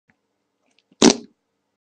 monke fart